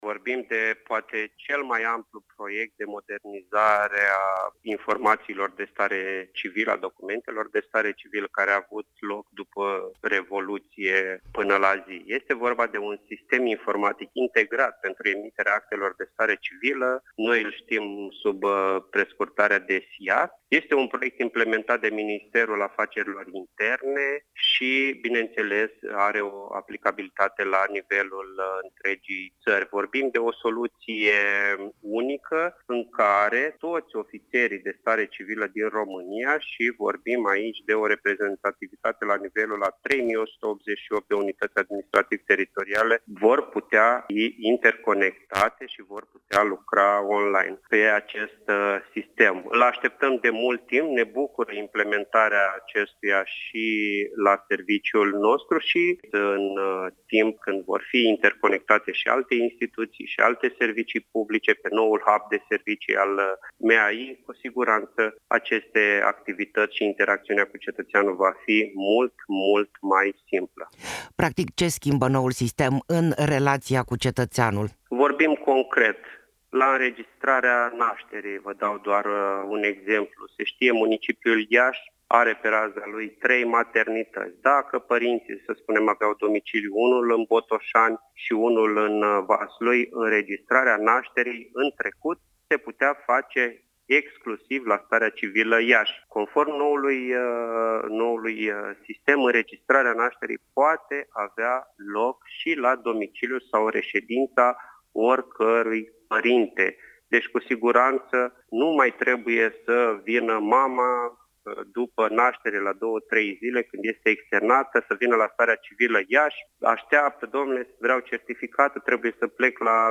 Interviu-Evidenta-populatiei-Iasi.mp3